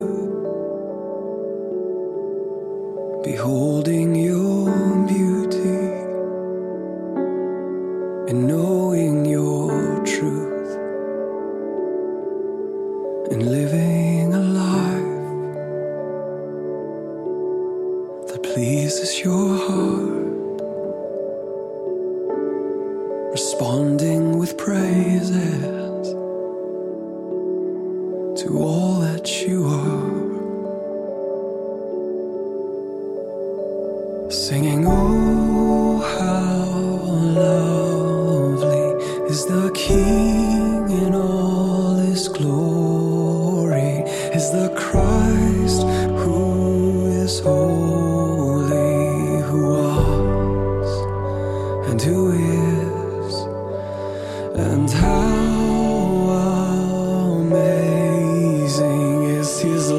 zeitgemäße, gemeindetaugliche Lobpreismusik
• Sachgebiet: Praise & Worship